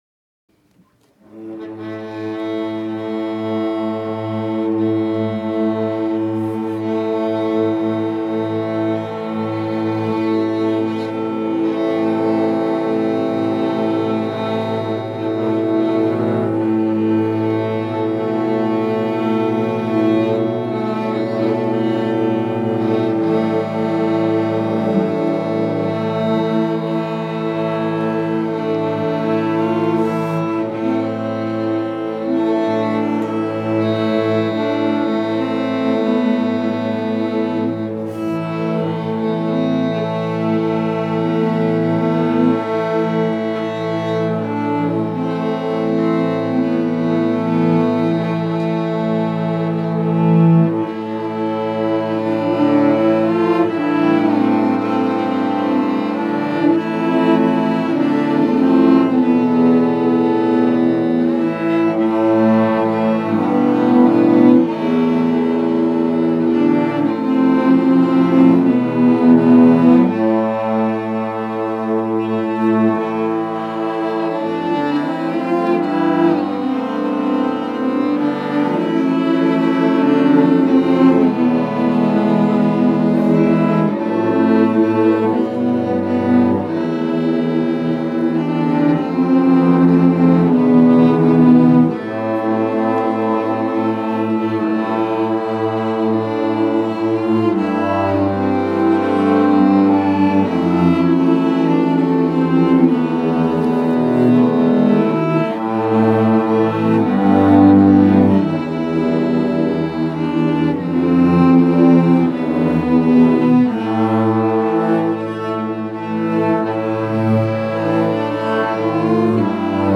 05_studio_violoncelles-melodie.mp3